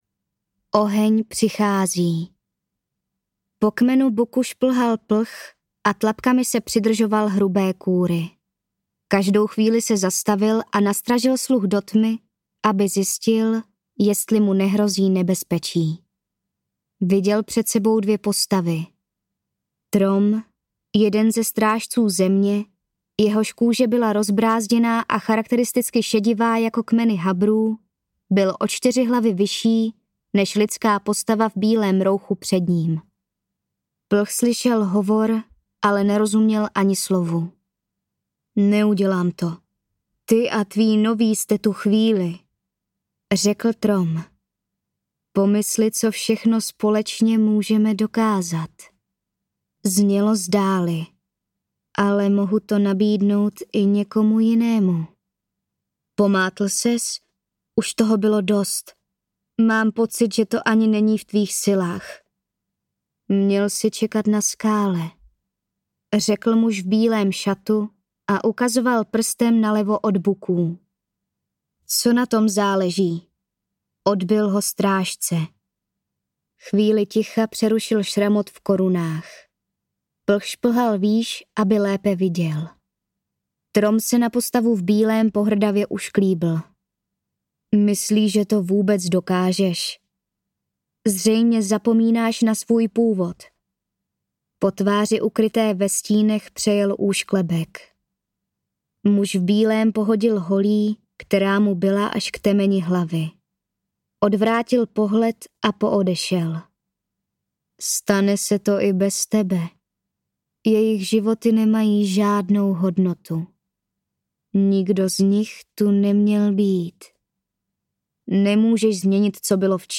Audiobook